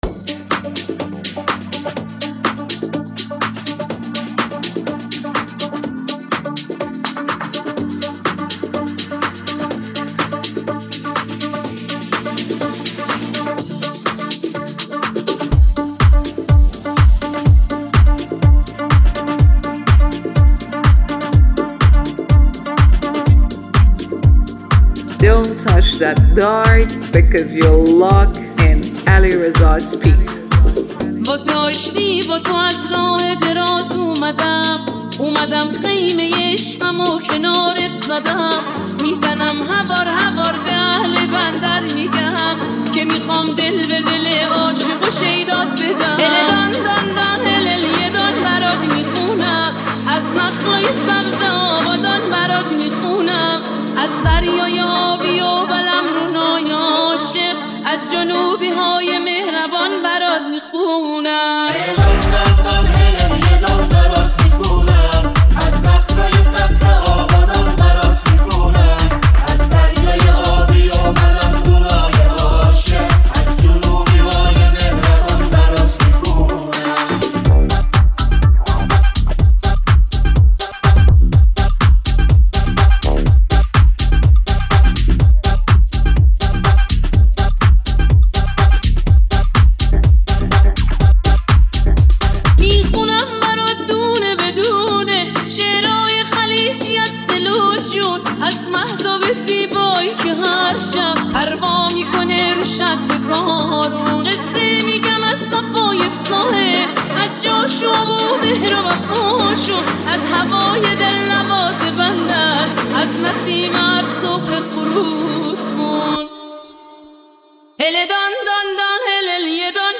نوستالژیک بندری
ریمیکس تند بیس دار